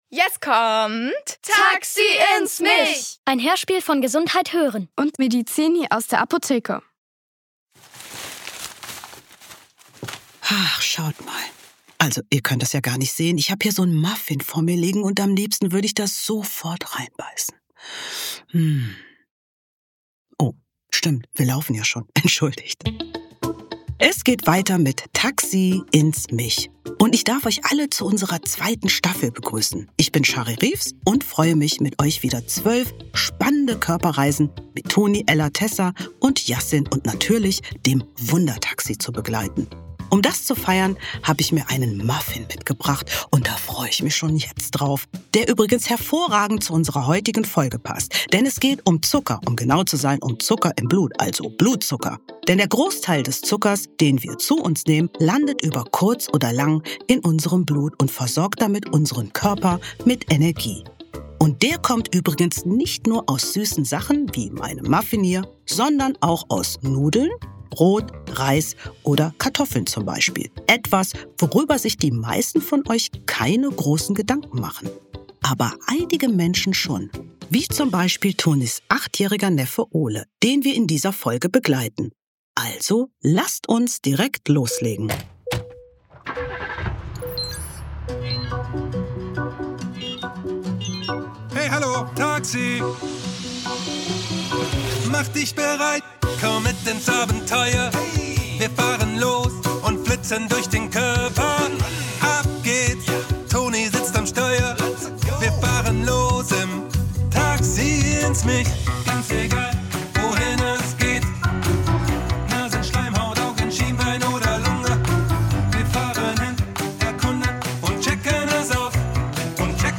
Süßes Blut ~ Taxi ins Mich | Der Hörspiel-Podcast für Kinder Podcast